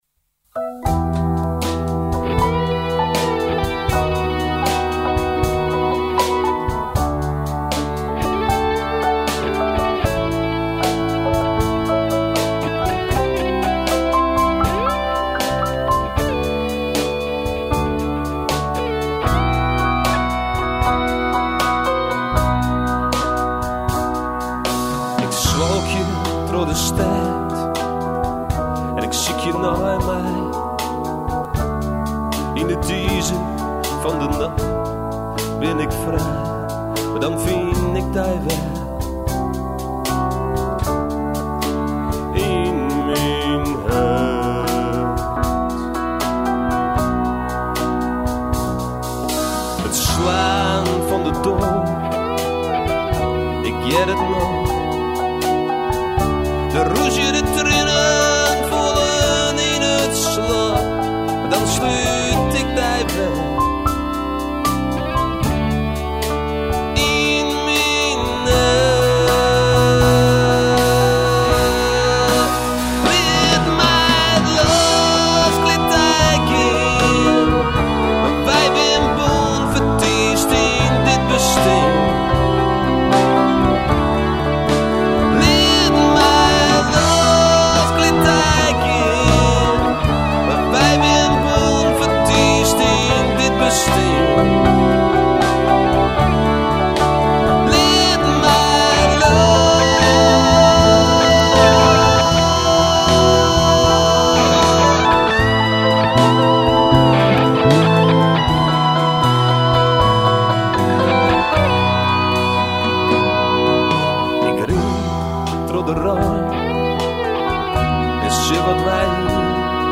Zang en keyboard
Piano
Gitaar
Drums